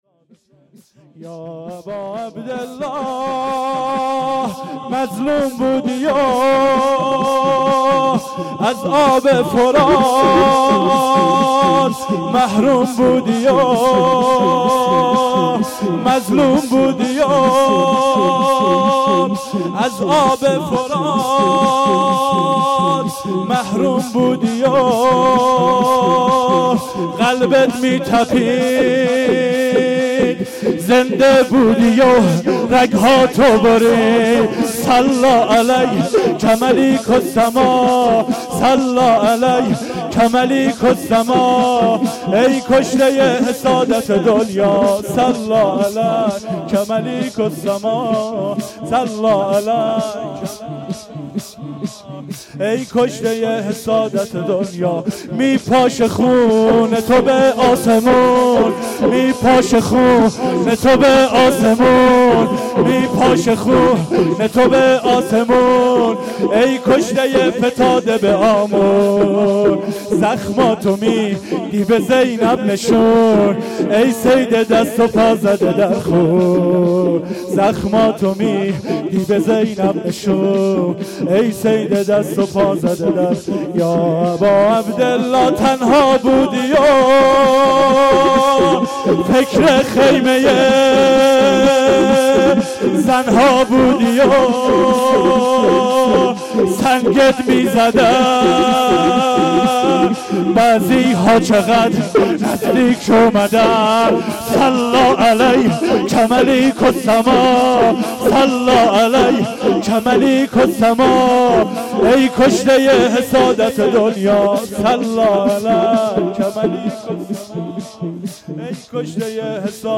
شور2